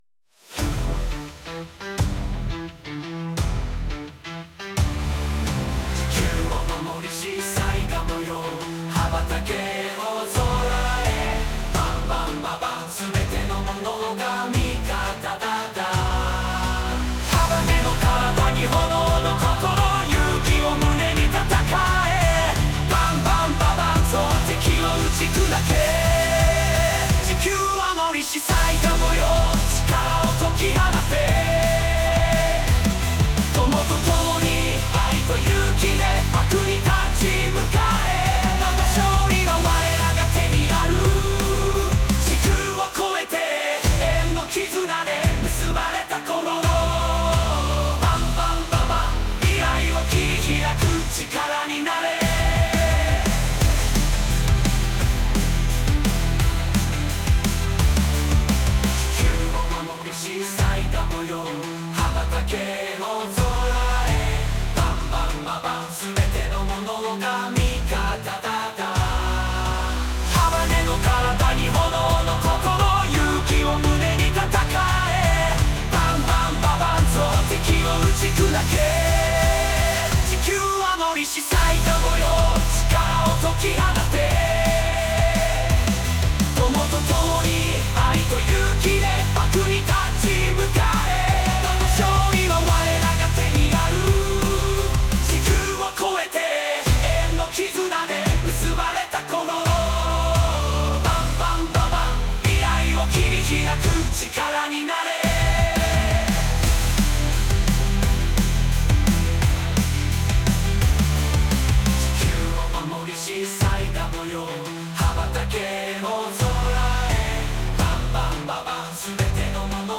音楽ファイルがない場合、SUNOで作ったMP3ファイルをどうぞ。